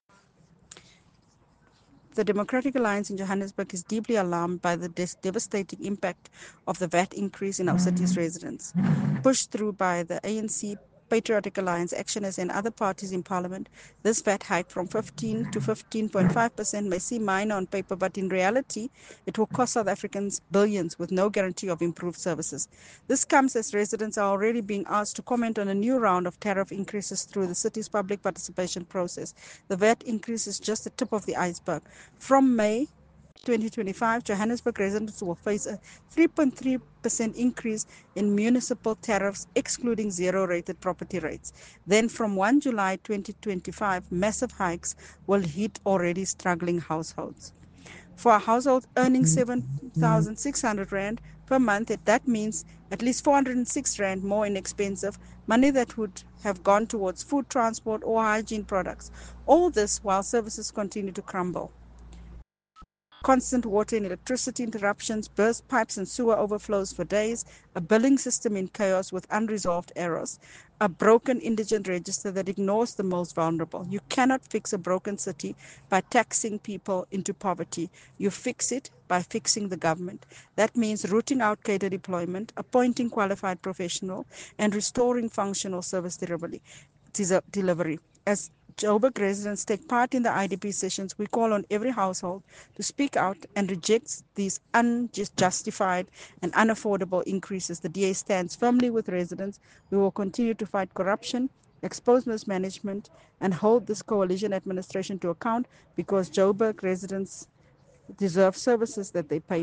Note to Editors: Please find a soundbite by Cllr Belinda Kayser-Echeozonjoku